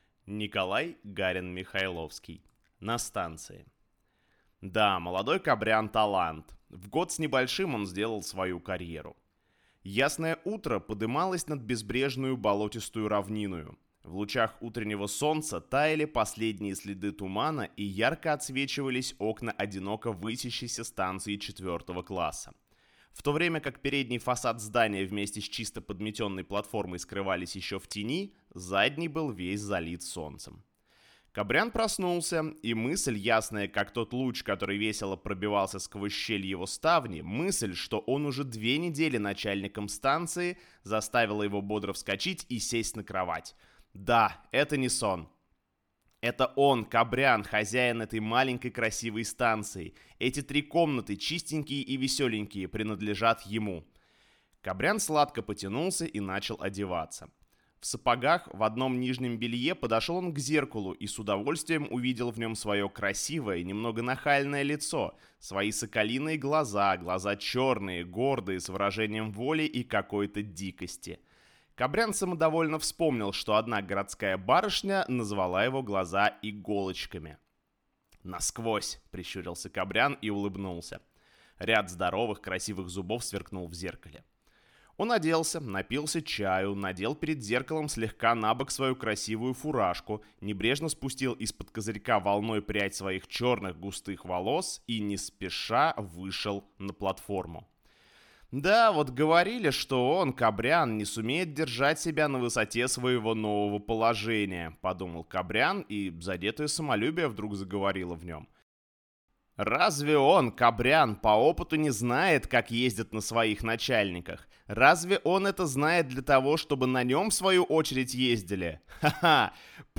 Аудиокнига На станции | Библиотека аудиокниг